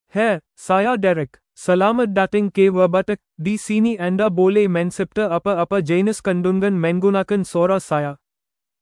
MaleMalayalam (India)
Derek is a male AI voice for Malayalam (India).
Voice sample
Male
Derek delivers clear pronunciation with authentic India Malayalam intonation, making your content sound professionally produced.